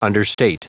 Prononciation du mot understate en anglais (fichier audio)
Prononciation du mot : understate